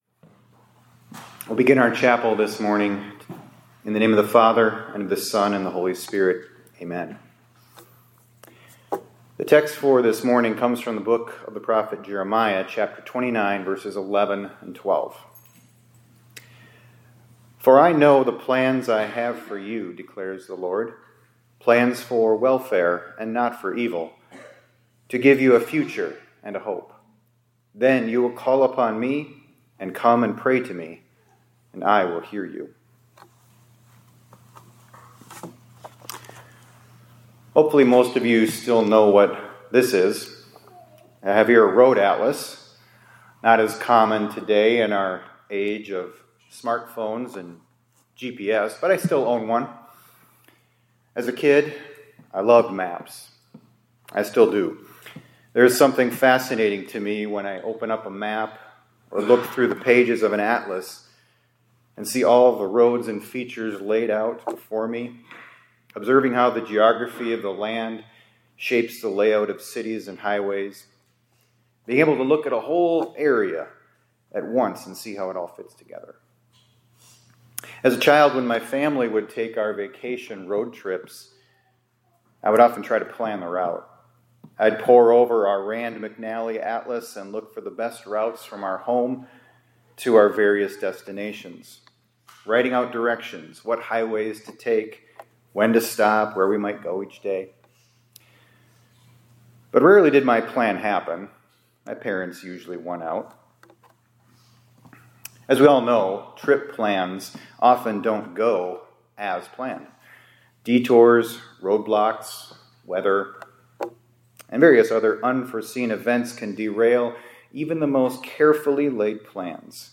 2026-02-17 ILC Chapel — The Plans of the Lord Will Prosper